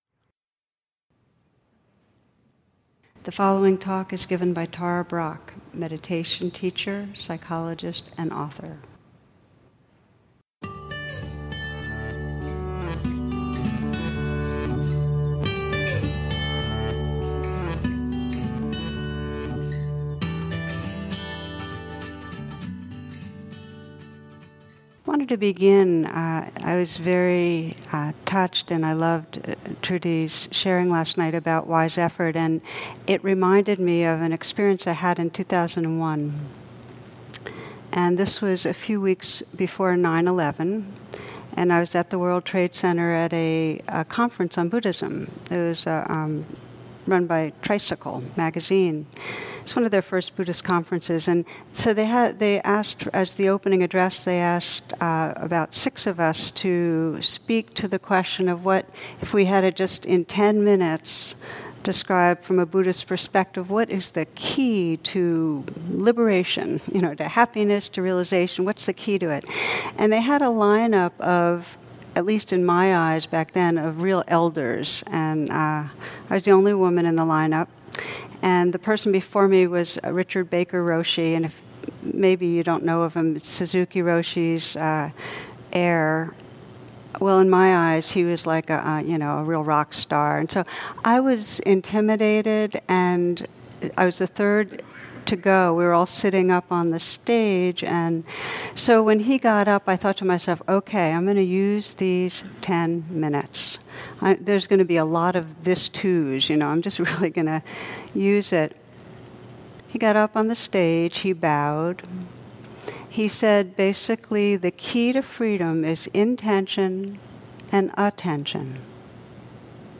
A retreat talk by Tara Brach.